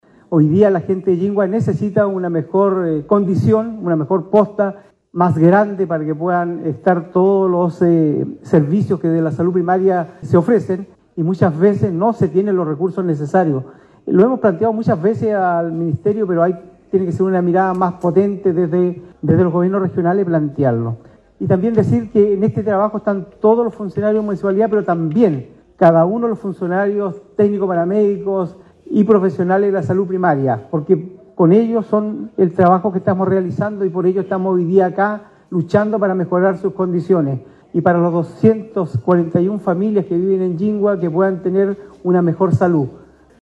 En tanto el Alcalde de Quinchao, René Garcés, expresó sus agradecimientos a los consejeros regionales y al Gobernador Regional por la aprobación del proyecto, recalcando que la nueva posta de Llingua, vendrá a mejorar las atenciones de salud de las familias y el lugar de trabajo de los profesionales que brindan las atenciones: